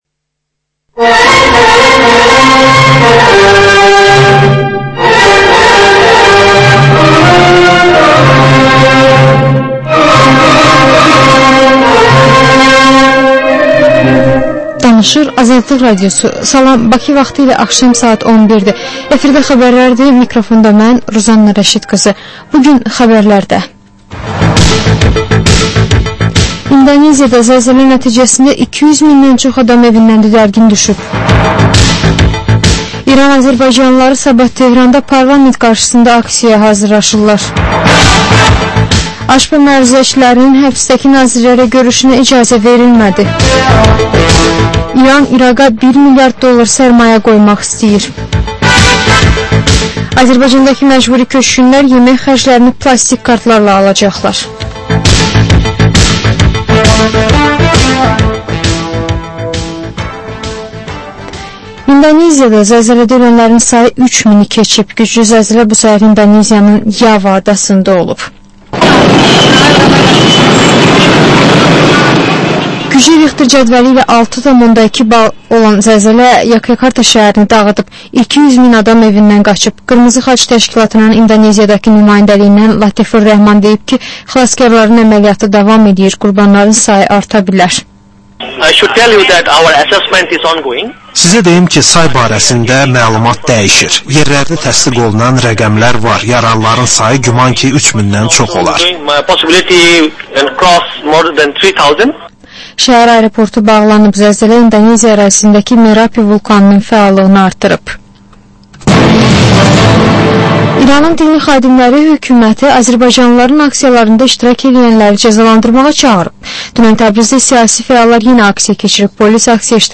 Xəbərlər, reportajlar, müsahibələr